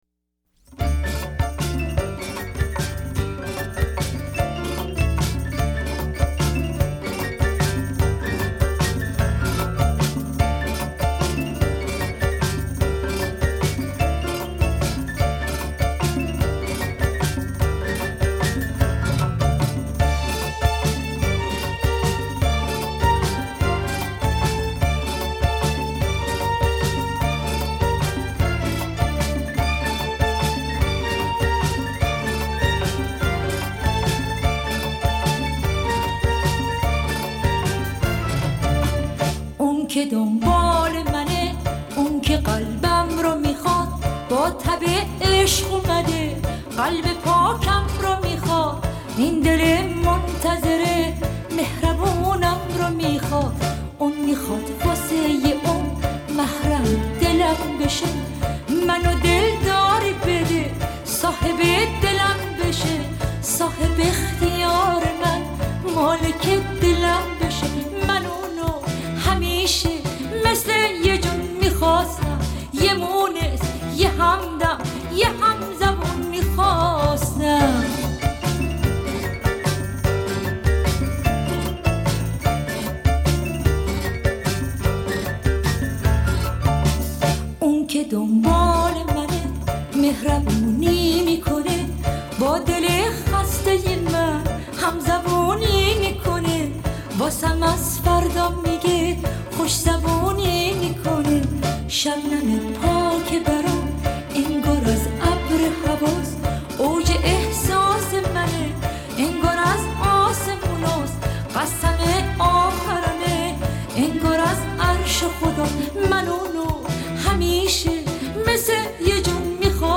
اهنگ شاد ایرانی اهنگ کردی اهنگ محلی